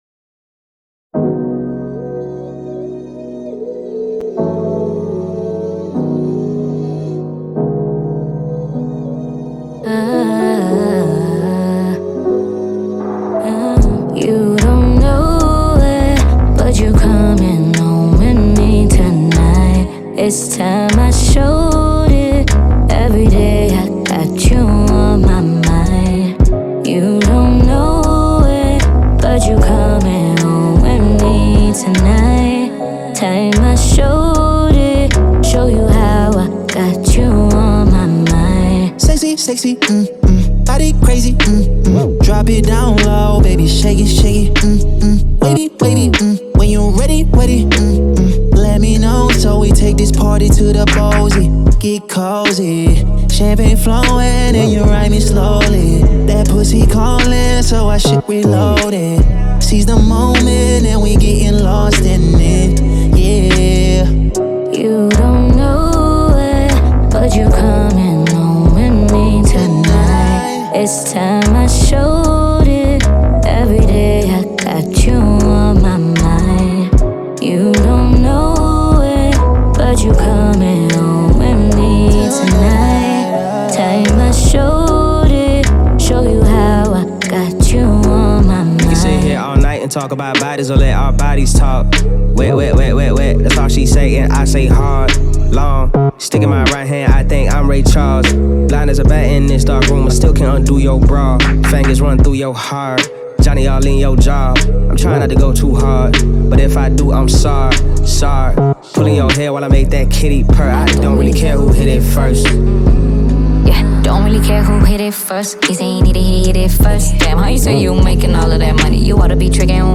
a heavily talented South African rap phenomenon.